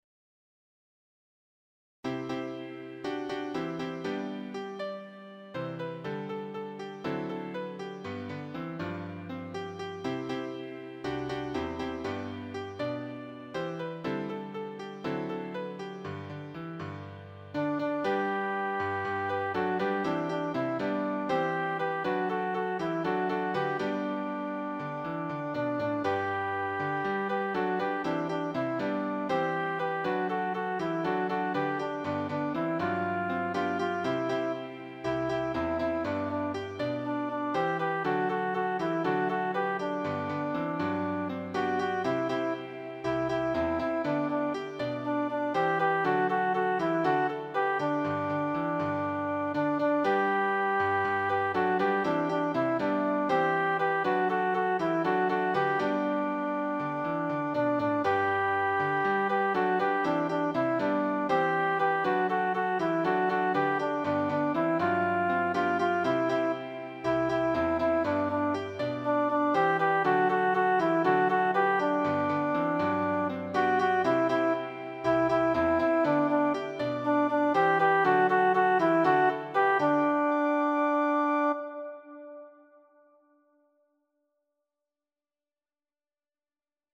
“Vamos a cantar a dos voces con los Zulúes de Sudáfrica„
2ª Voz